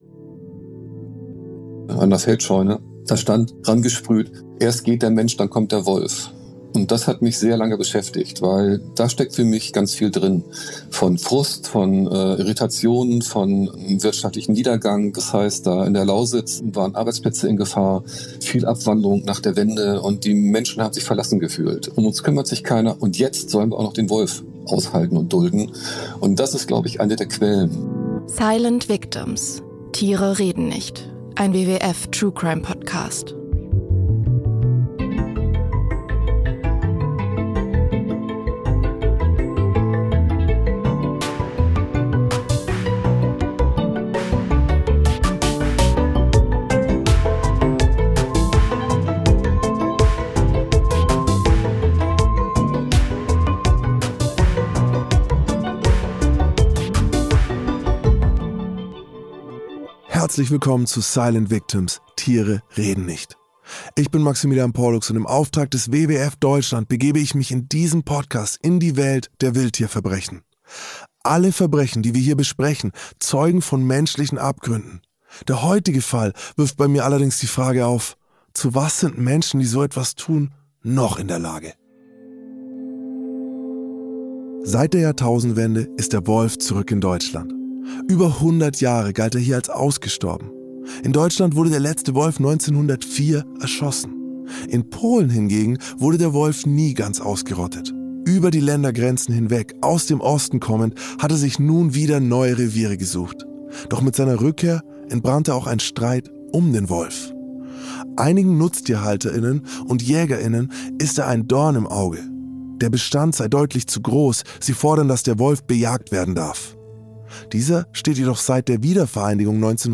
In jeder Episode tauchen wir ab in die Lebenswelt der stummen Protagonisten aus dem Tierreich. Im Hörspiel-Stil bekommen wir einen Einblick in die Lebensrealität der Opfer und erfahren Details über die einzelnen Verbrechen.